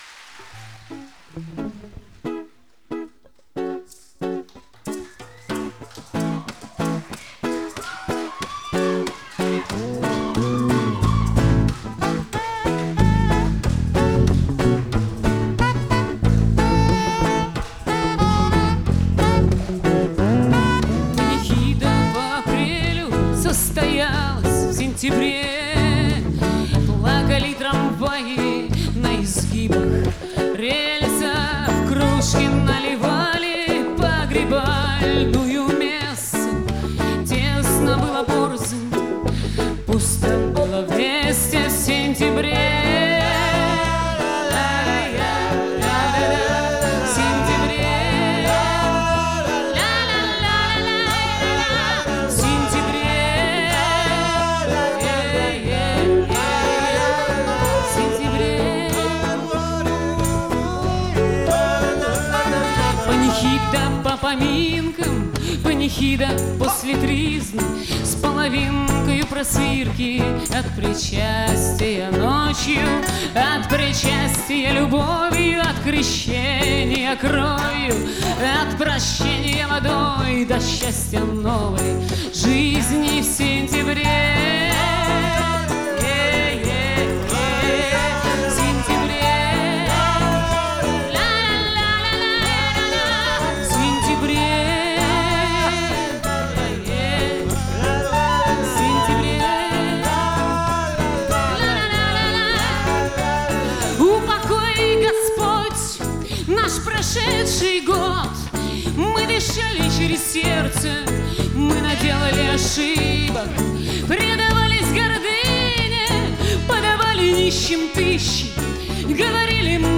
Концертный диск, стиль — акустика.
голос, гитара
бас, мандолина, голос
перкуссия
саксофон, флейта